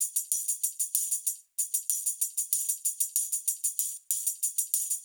SSF_TambProc2_95-02.wav